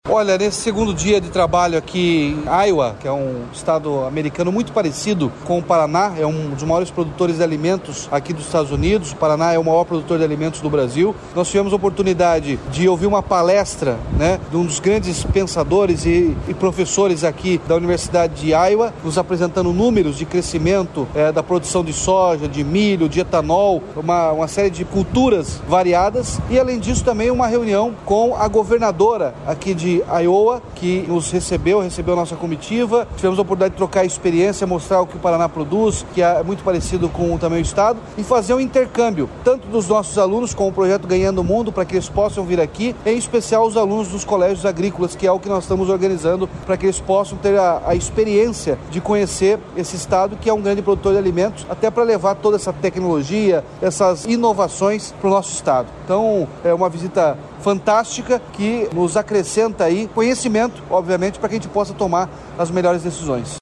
Sonora do governador Ratinho Junior sobre reunião com a governadora de Iowa para trocar experiências entre o Paraná e o estado norte-americano